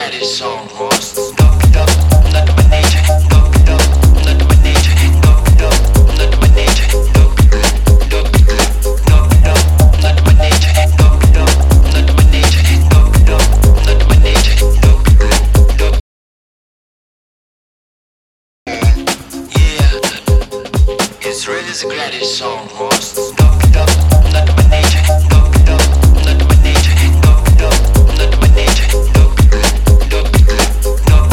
Но экспортированные со сжатием в Variable Bitrate (то есть каждый участок кодируется индивидуально) Попробуйте вычесть их.